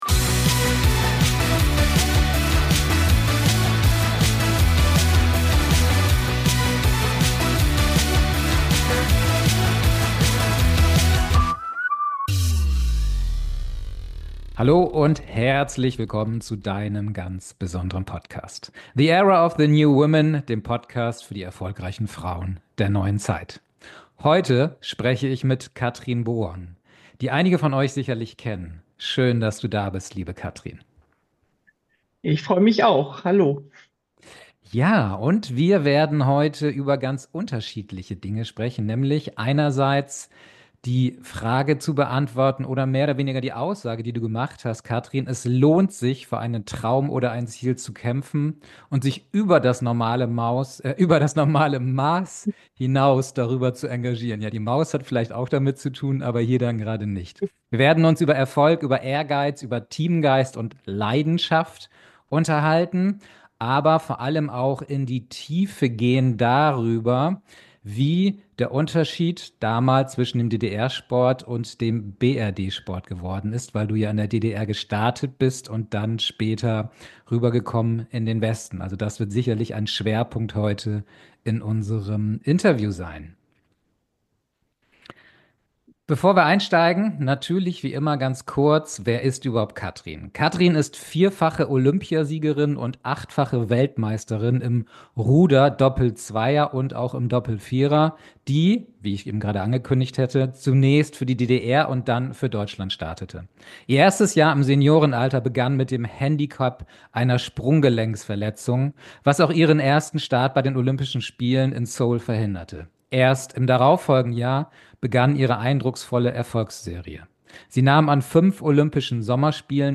#048 Und dann war das DDR-System einfach weg. Das Interview mit Olympiasiegerin Kathrin Boron ~ The Era of the New Women Podcast